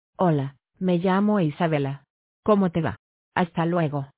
Premium International Voices
Female Spanish (Latin America)